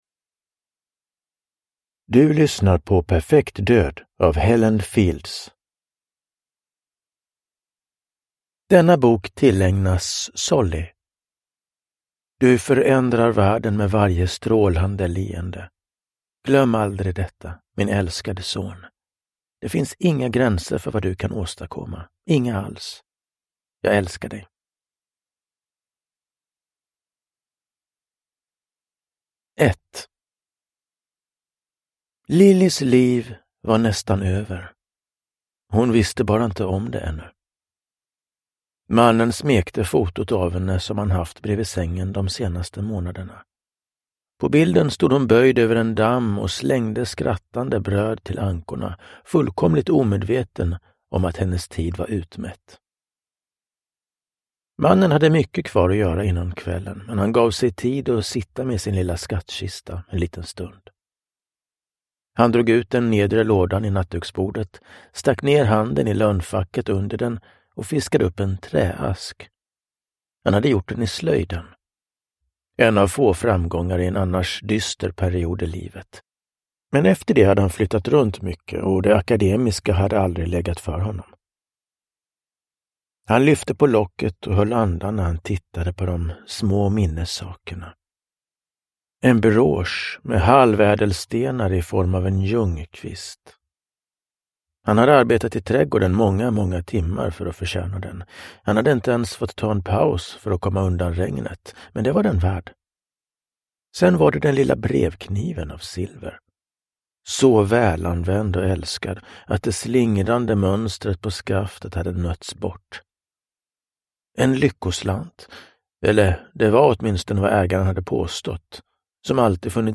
Perfekt död – Ljudbok – Laddas ner